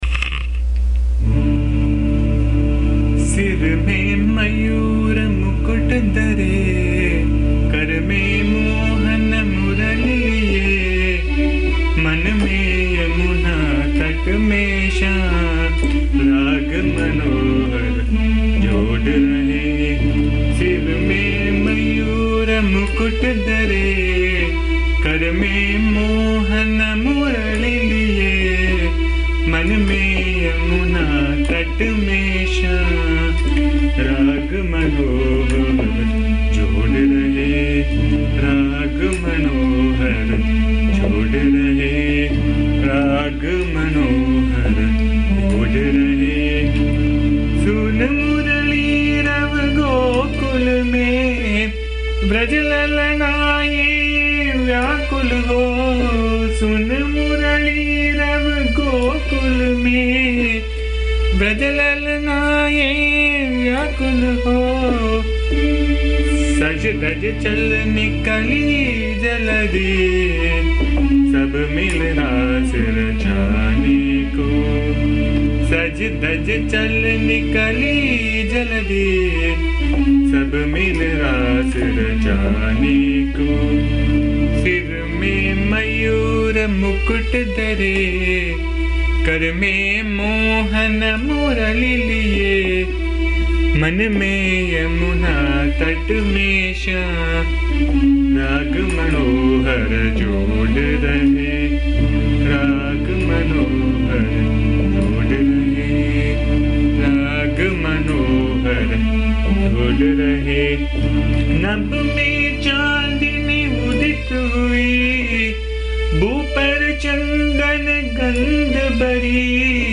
This song is set in Des Raag. The words are meaningful and beautiful, the tune is ecstatic and can lead any devotee to Samadhi.
Hence have recorded the song in my voice which can be found here. Please bear the noise, disturbance and awful singing as am not a singer.
AMMA's bhajan song